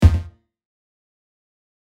Buttons and Beeps
Error 7.mp3